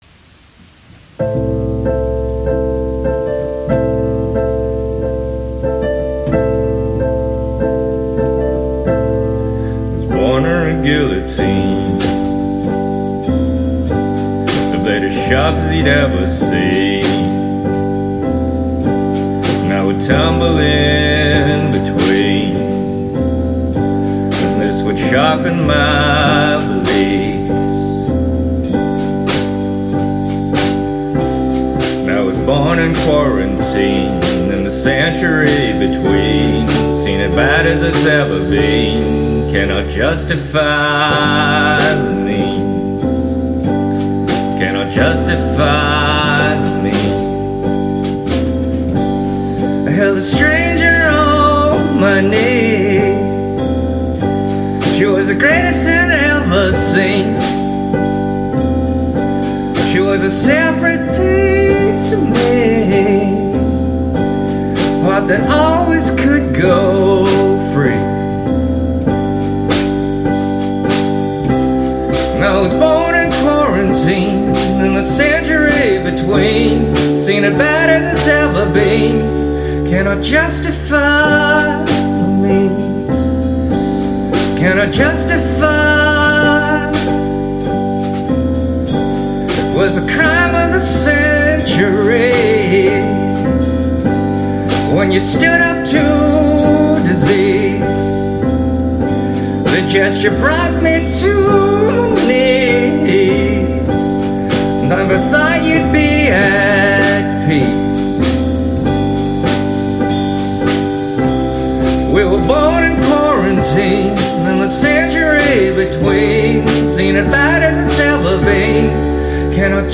music (performing arts genre)
It was and is literally my Asian Canadian experience with the COVID quarantine. I had to do some cutting and rearranging to fit the song under the 3 minute time limit, so this is the "radio edit".